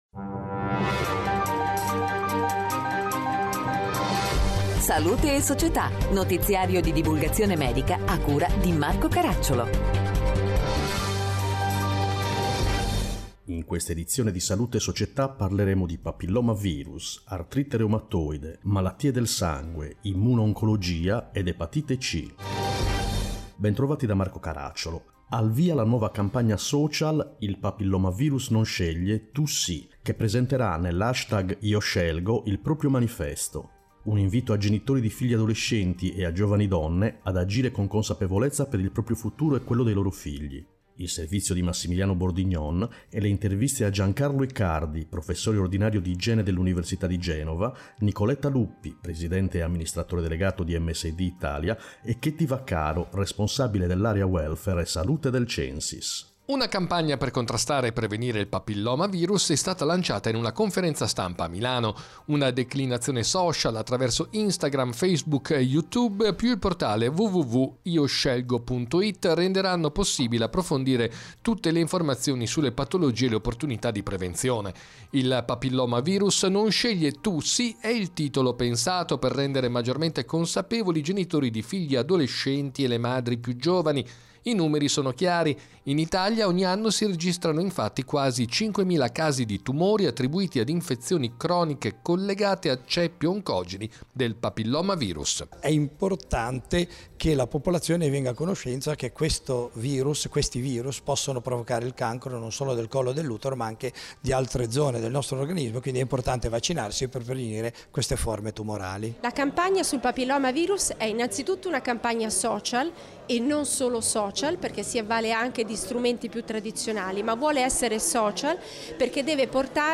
Interviste: